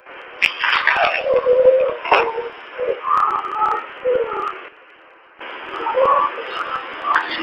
EVP's
Here is a slightly slower version of the previous evp.